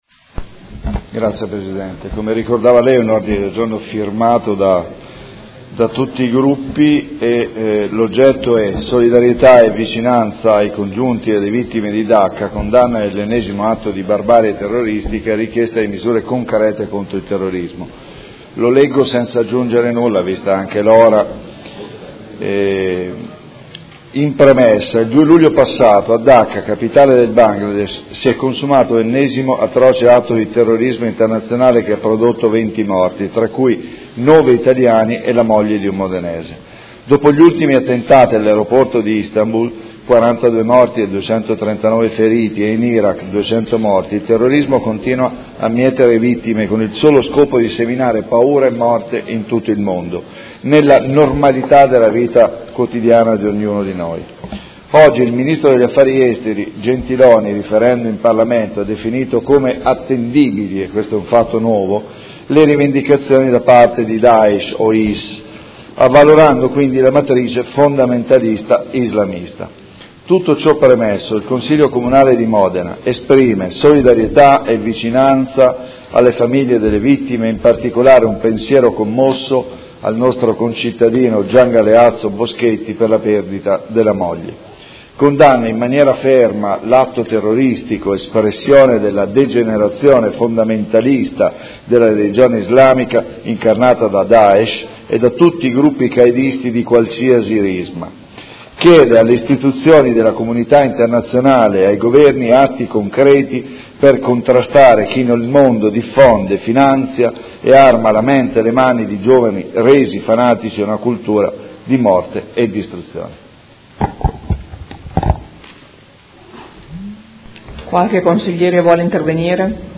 Seduta del 7 luglio. Ordine del Giorno n° 102235 presentato da tutti i capigruppo in Consiglio Comunale